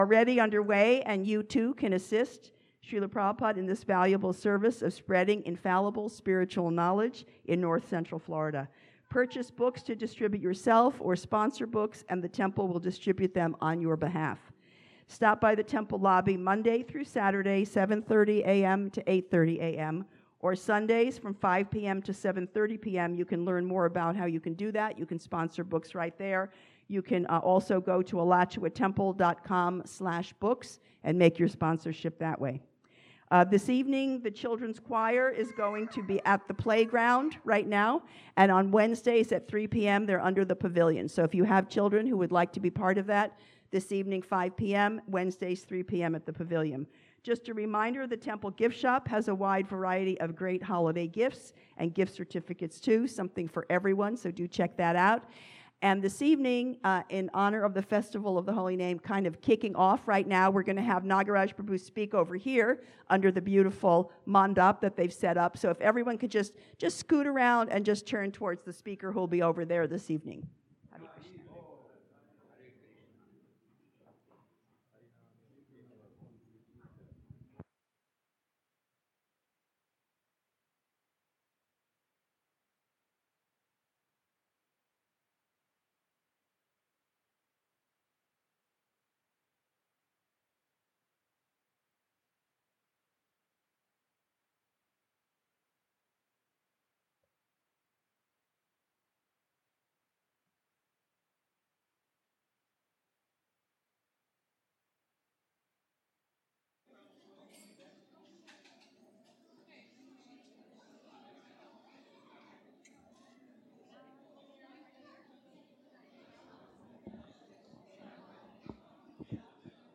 Sunday Feast Lecture
2025. at the Hare Krishna Temple in Alachua,...